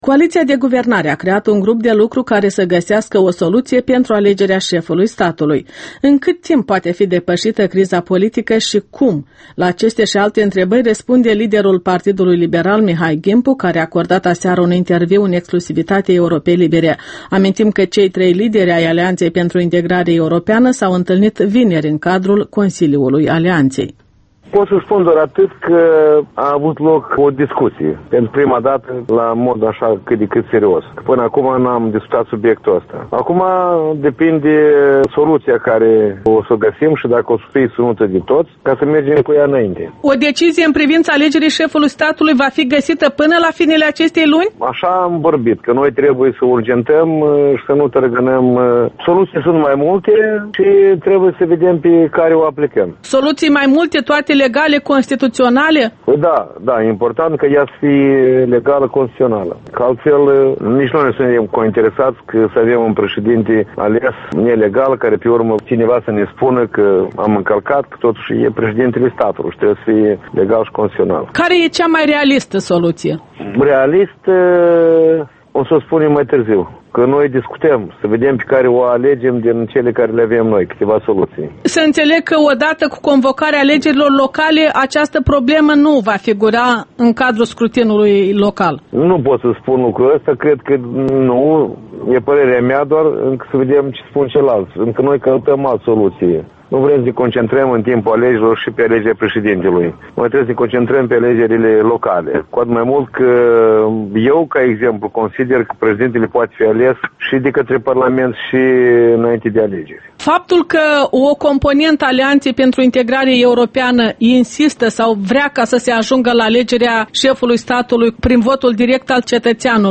Interviul matinal EL: cu Mihai Ghimpu despre AIE, alegerea președintelui și alegerile locale